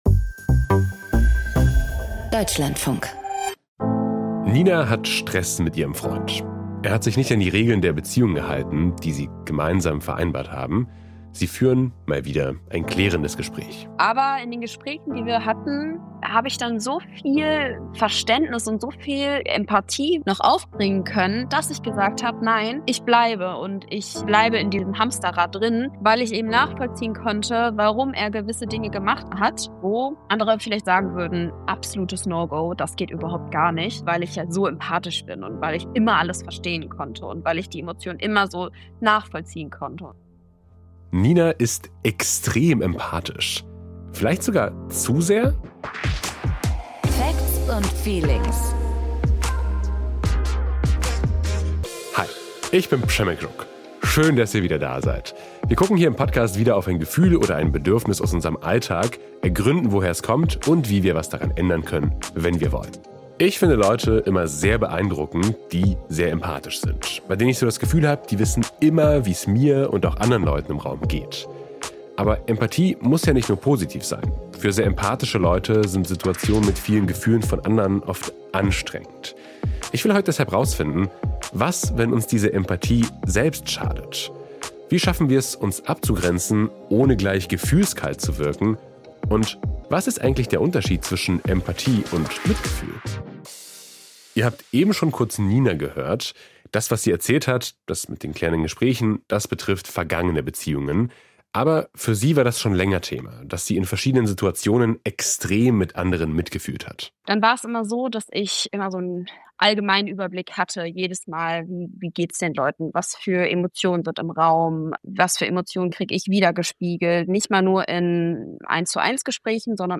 Eine Systemische Therapeutin gibt Tipps, um sich besser abzugrenzen.